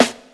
• 90's Good Acoustic Snare Sound B Key 118.wav
Royality free steel snare drum sample tuned to the B note.
90s-good-acoustic-snare-sound-b-key-118-hVb.wav